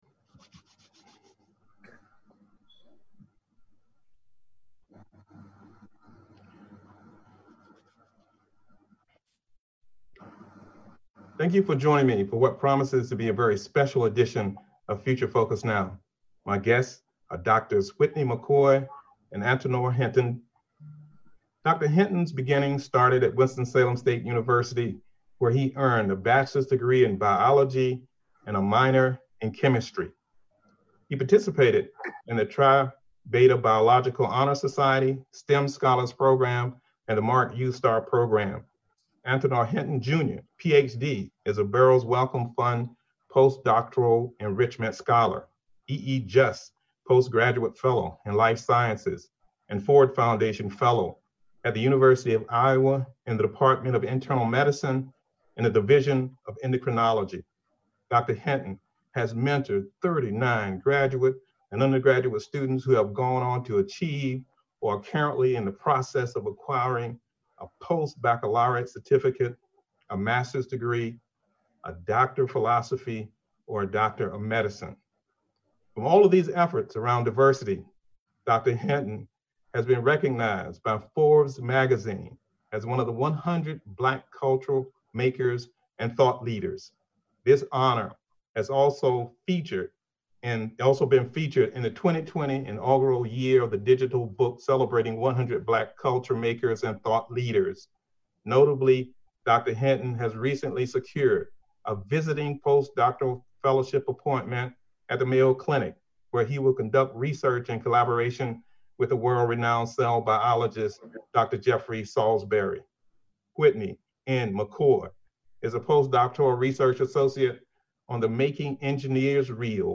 Future Focus is a one-hour public affairs talk show